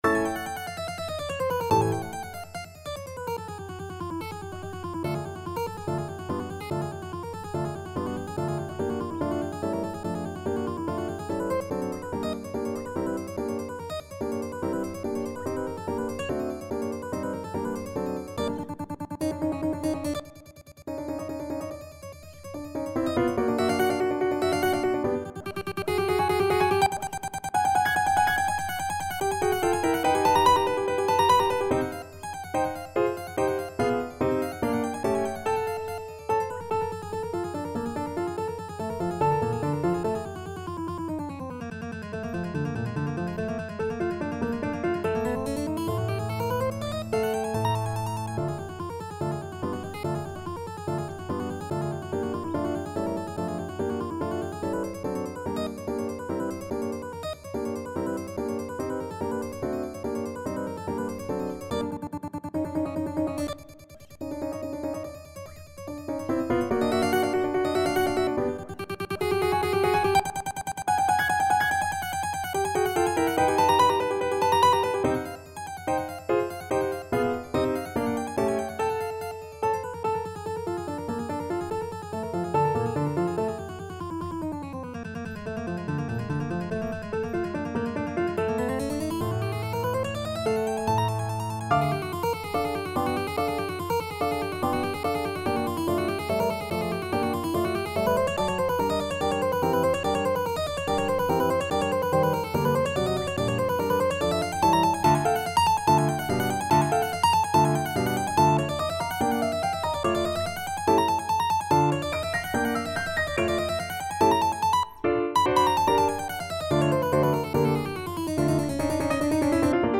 Mandolin
2/4 (View more 2/4 Music)
F major (Sounding Pitch) (View more F major Music for Mandolin )
Vivace = 144 (View more music marked Vivace)
Classical (View more Classical Mandolin Music)